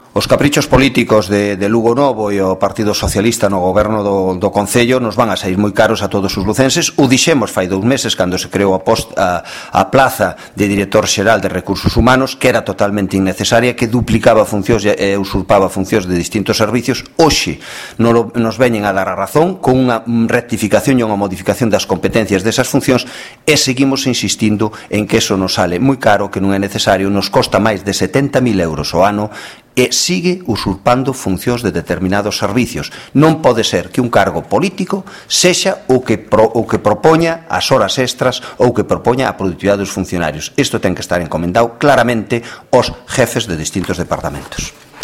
O voceiro do Grupo Municipal do Partido Popular afirmou esta mañá na súa intervención plenaria relativa á Dirección Xeral de Persoal que se trata dun “cargo político” creado “polo capricho de Lugonovo”.